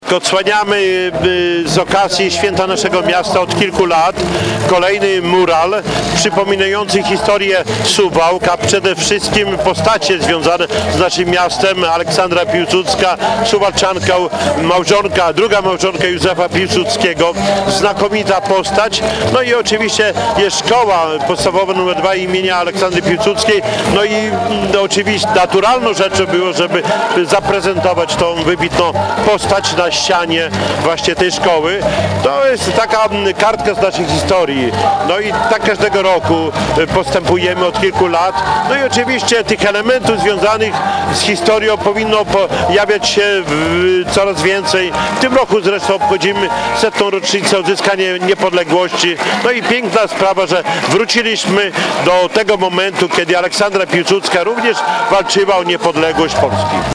– Mural wpisuje się również w tegoroczne obchody 100 rocznicy odzyskania przez Polskę niepodległości – mówił Czesław Renkiewicz, włodarz miasta.